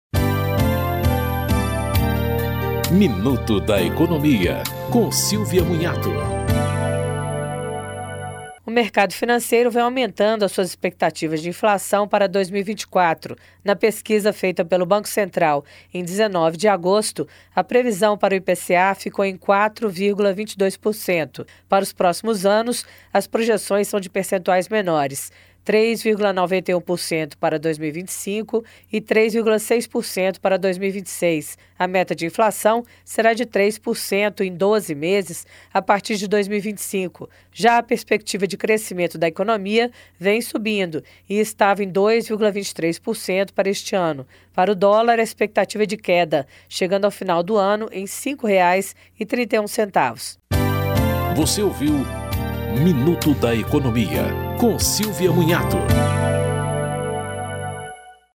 Apresentação